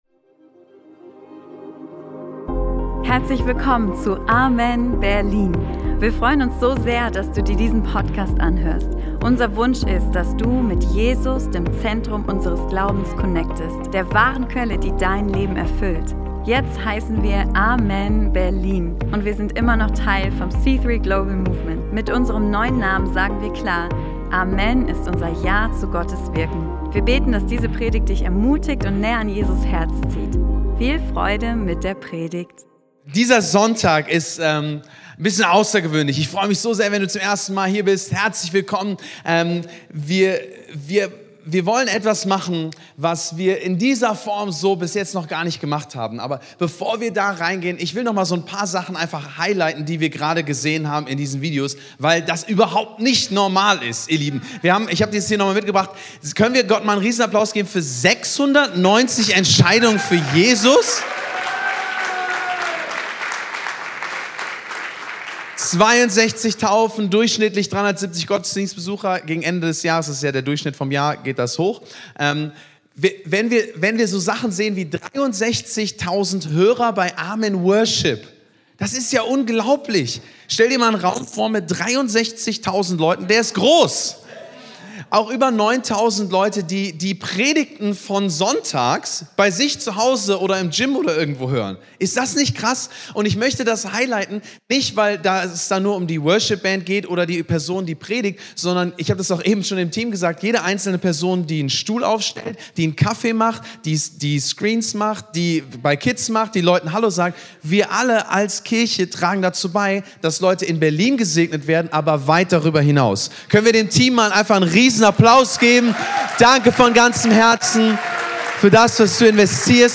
Vision Sunday bedeutet, ehrlich zu teilen, was Gott uns als Kirche aufs Herz gelegt hat. In dieser Predigt geht es um Jesaja 61, um Jesu Auftrag – und darum, wie eine Vision für viele mit einem Herzen für den Einen beginnt.